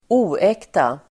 Uttal: [²'o:ek:ta]